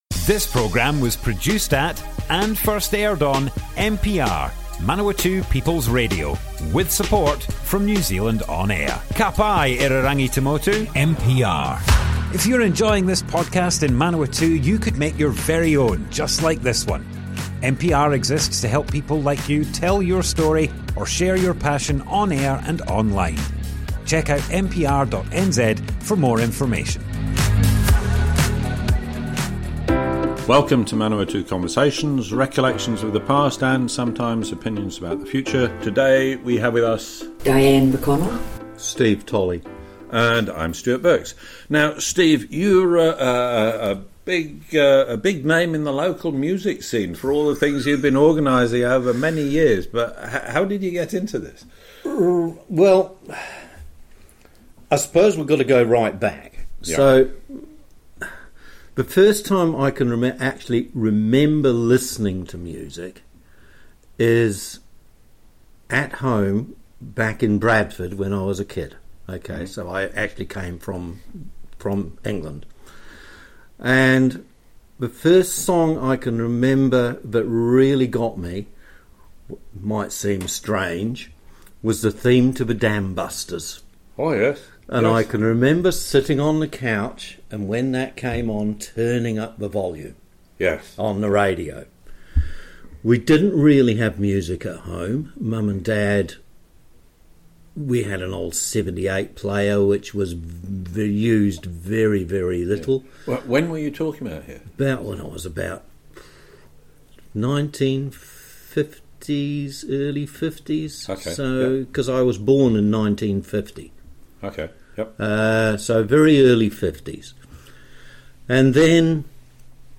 Manawatu Conversations More Info → Description Broadcast on Manawatu People's Radio, 26th August 2025.
oral history